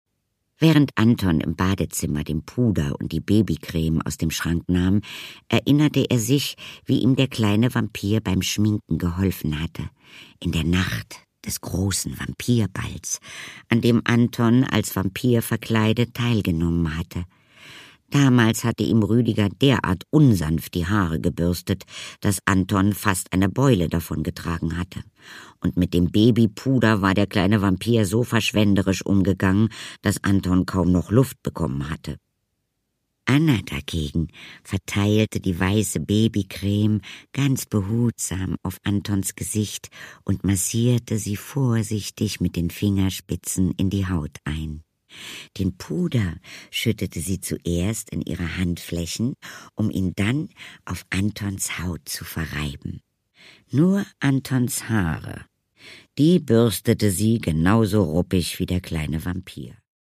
Produkttyp: Hörbuch-Download
Gelesen von: Katharina Thalbach